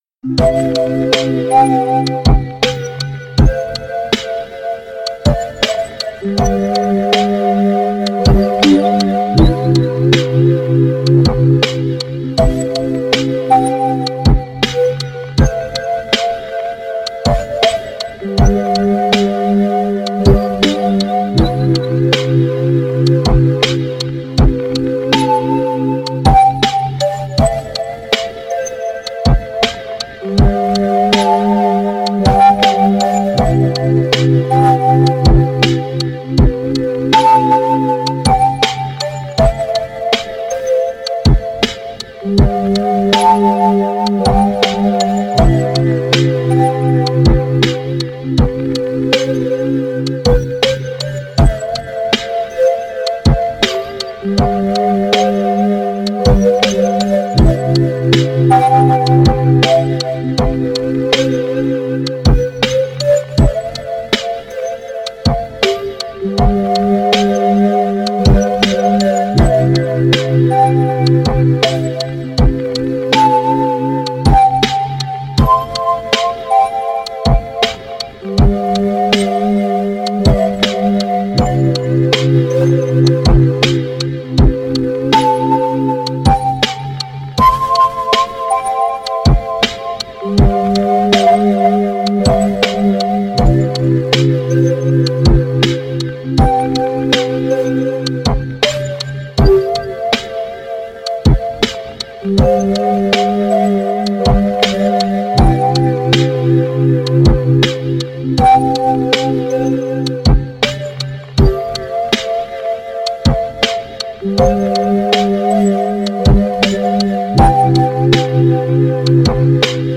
気怠いLo-fiです。【BPM80】